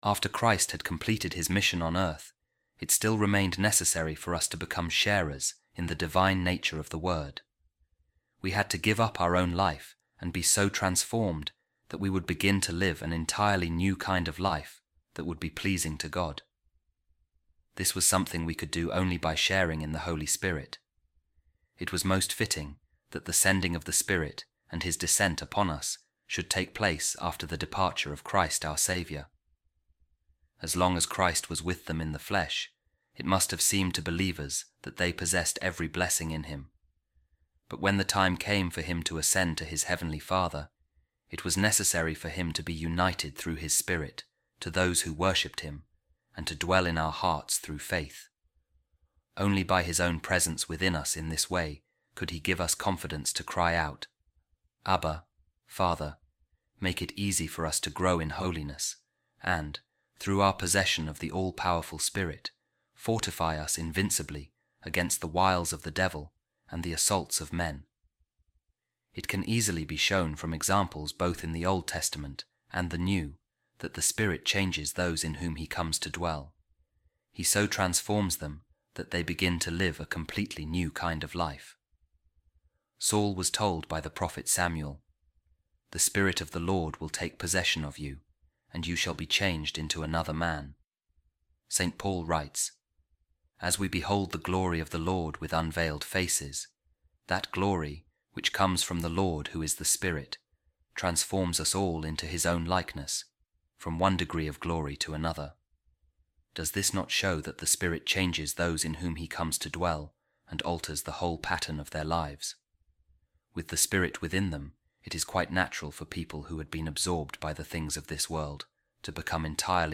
A Reading From The Commentary On The Gospel Of Saint John By Saint Cyril Of Alexandria